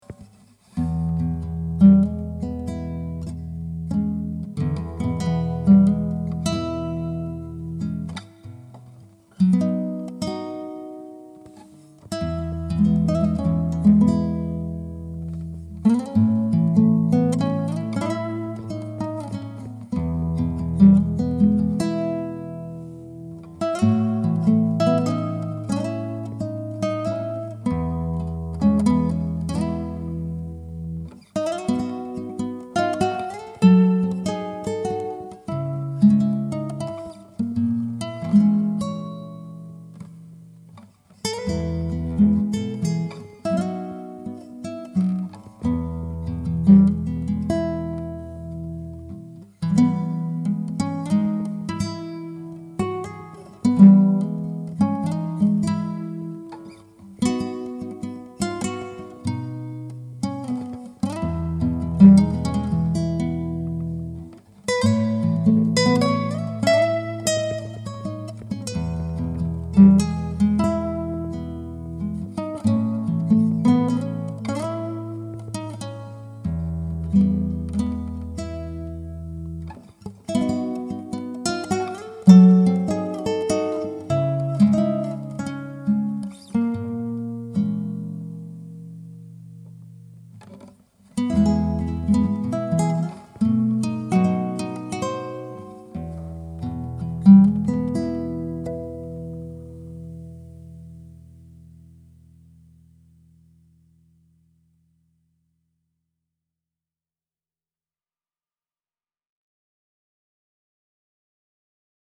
Como no quiero ser un Ebenezer Scrooge de la vida en estas fechas, subo una interpretación particular (un poco tristona) de la melodía para compartirla…